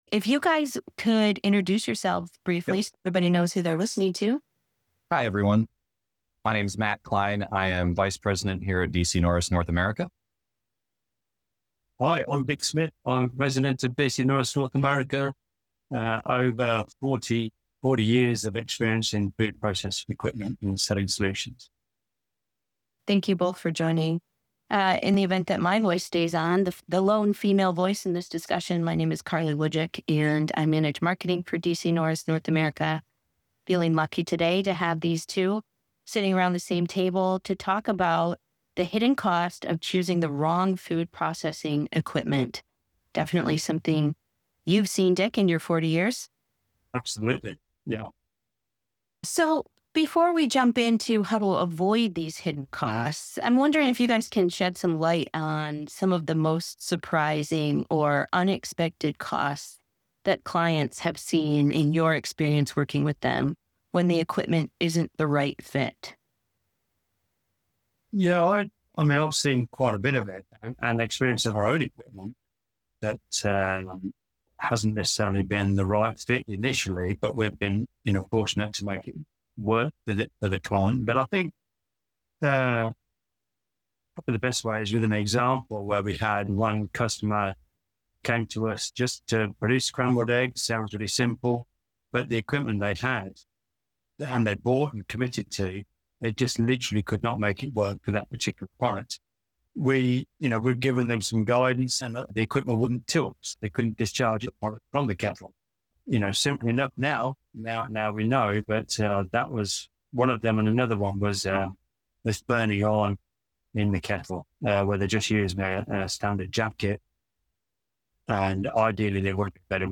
Our Experts Discuss: How to Avoid Choosing the Wrong Food Processing Equipment